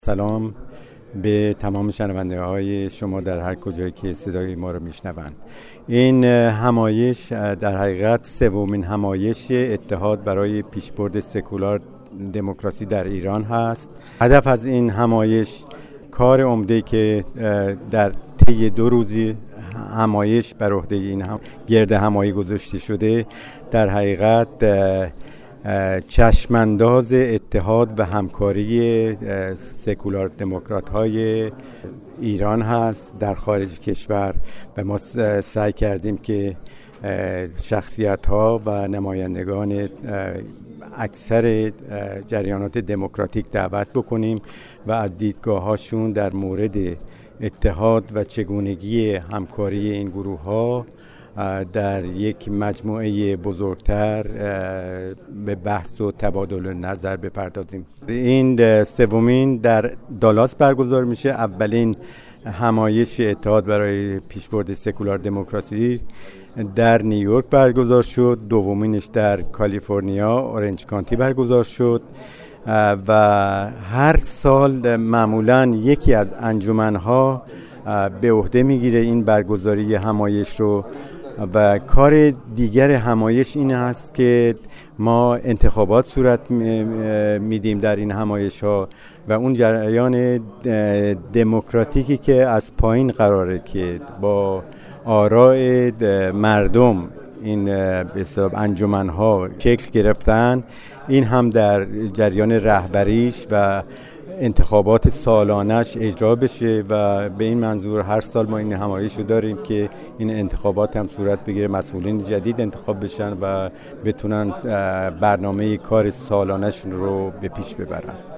بخشی از اظهارات